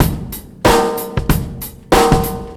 Memphis Soul 92bpm.wav